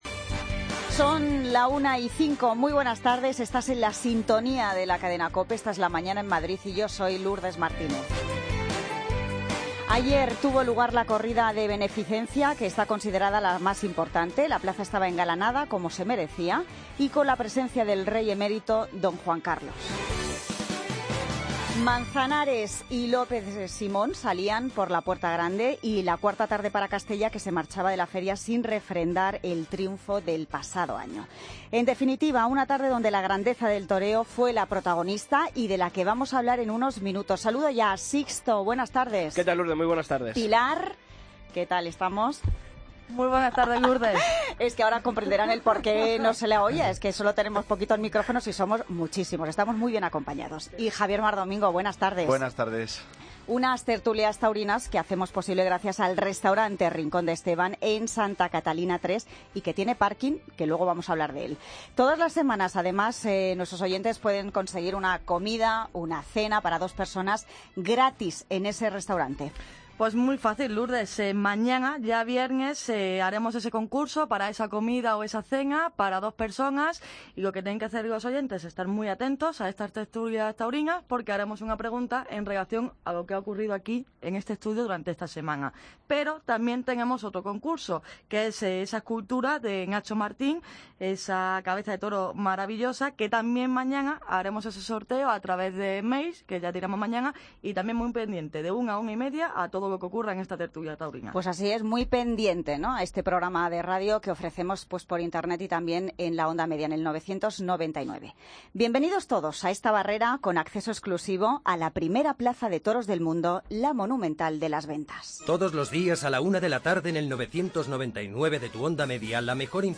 Tertulia Taurina Feria San Isidro COPE Madrid, jueves 2 de junio de 2016